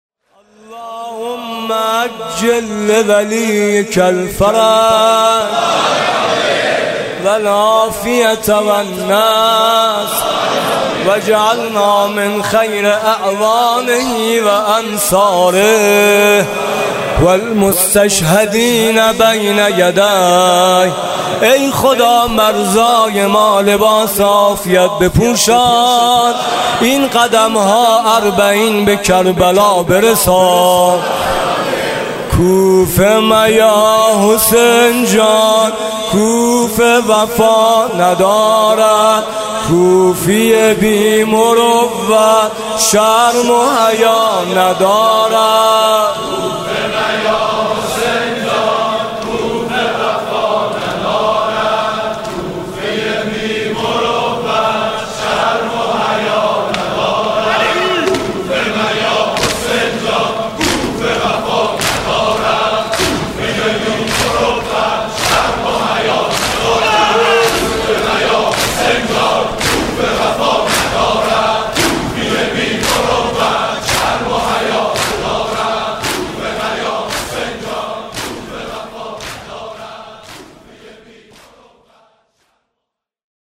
صوت مراسم شب اول محرم 1438 هیئت میثاق با شهدا ذیلاً می‌آید:
شور: چگونه دل دهد، به دنیا سینه زن | برادر میثم مطیعی